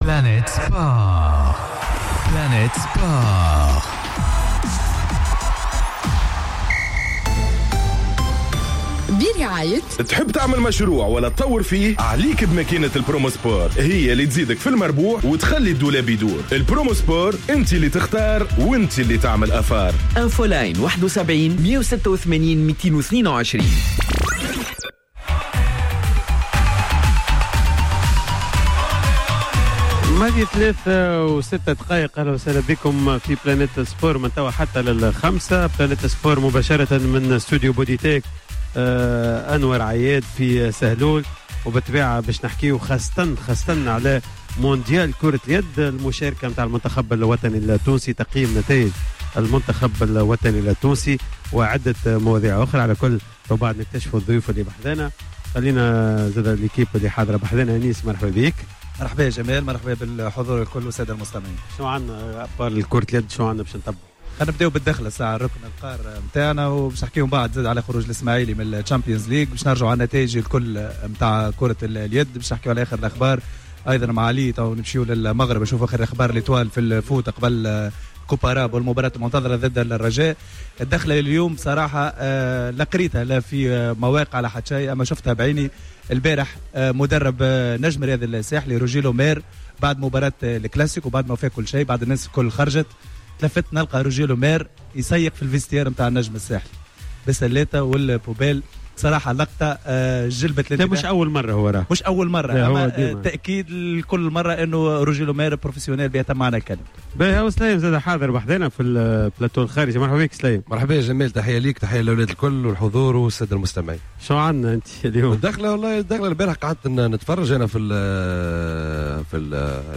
خصصت حصة "Planète Sport" ليوم الخميس 24 جانفي 2019 و التي بثت مباشرة من إستوديو bodytec سهلول لتقييم مشاركة المنتخب الوطني التونسي في منافسات بطولة العالم لكرة اليد ألمانيا الدنمارك 2019.